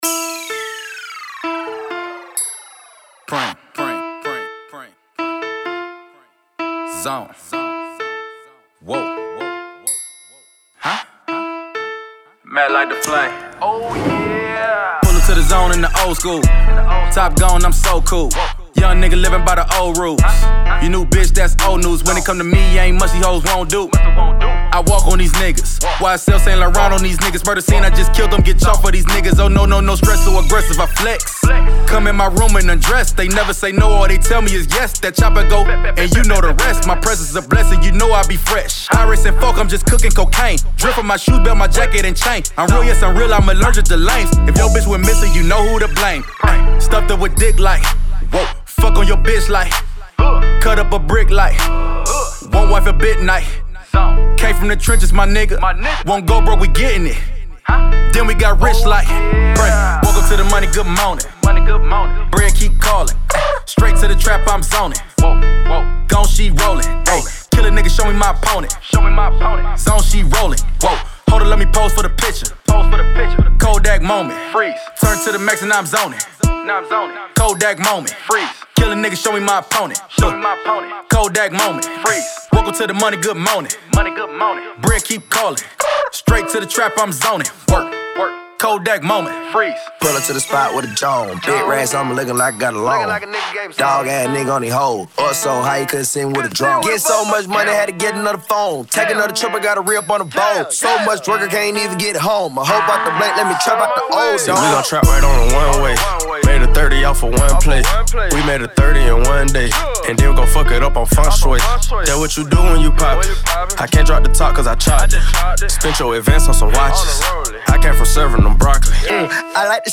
Hiphop Posted